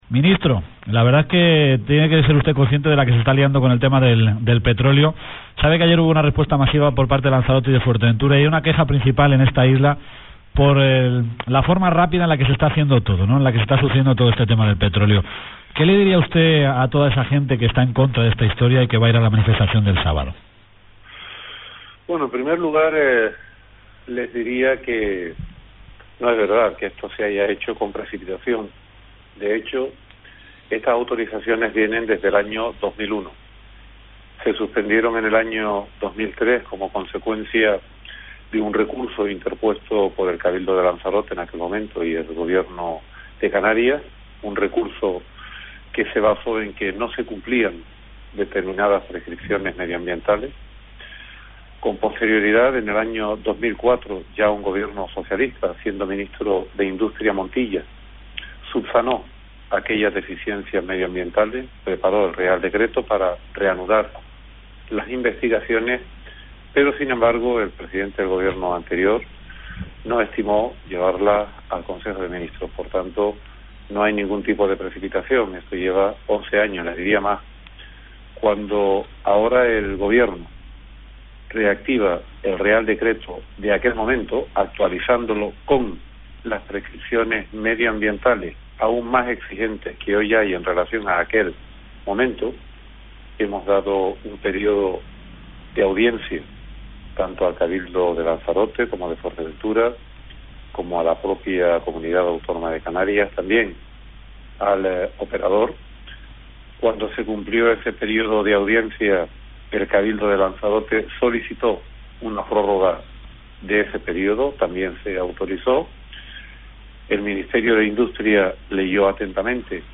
Cronicas_Radio_Entrevista_JMS.mp3